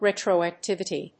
/ˌrɛtroæˈktɪvɪti(米国英語), ˌretrəʊæˈktɪvɪti:(英国英語)/